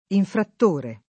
infrattore [ infratt 1 re ]